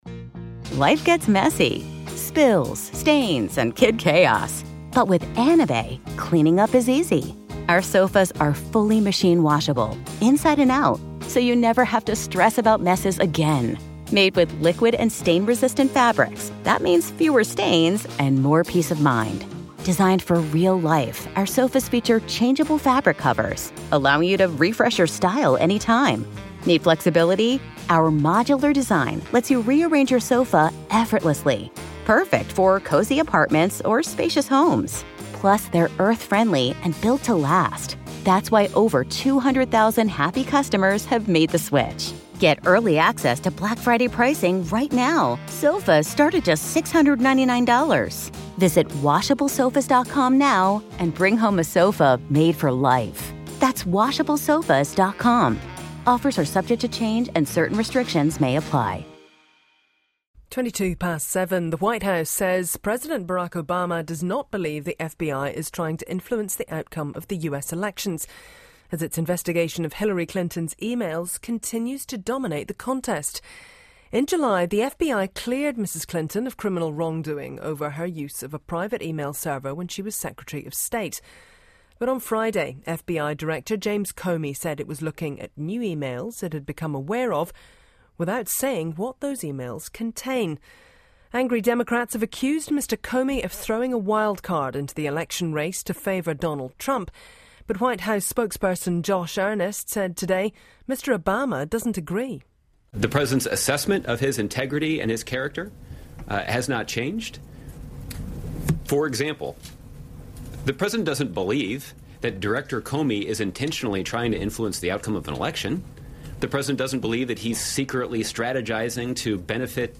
report aired on Radio New Zealand's "Morning Report"